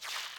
3 Harsh Realm Vox Clicks Short.wav